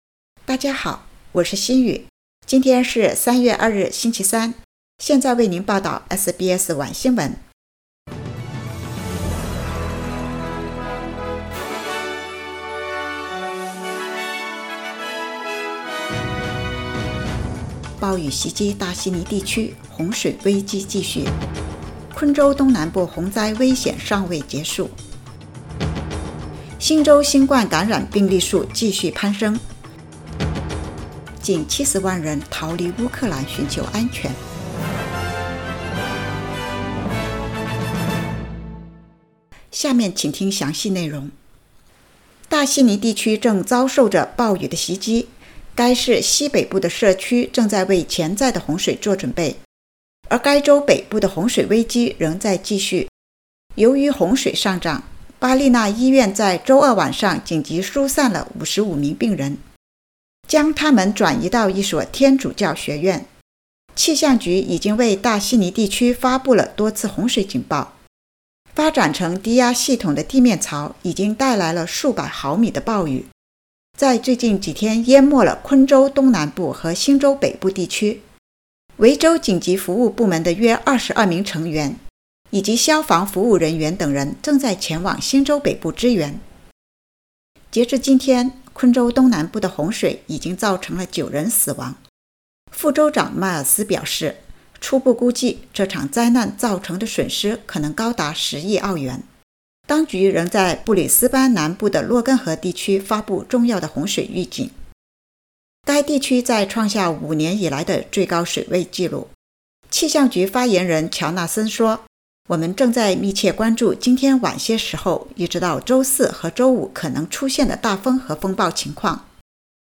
SBS晚新闻（3月2日）
SBS Mandarin evening news Source: Getty Images